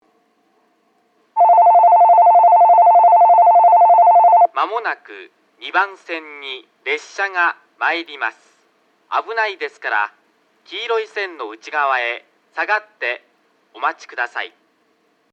2番のりば接近放送　男声
放送はかなり特殊で、ベルはJR九州標準のベルでなく、ごく普通な電子ベルが流れ、「1番のりば」ではなく、「1番線」と放送されます。
放送はラッパ型から流れ、並列して設置されているクリアホーンからは遠隔の予告放送が流れます。